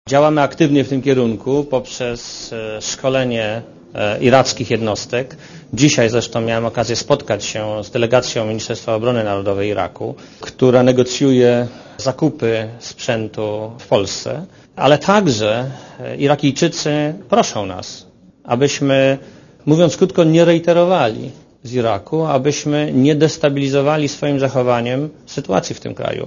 „Nie zmieniamy naszej polityki wobec Iraku i pobytu naszych wojsk w tym kraju” – powiedział w czasie Międzynarodowego Salonu Przemysłu Obronnego w Kielcach Marek Belka.
Posłuchaj komentarza premiera Belki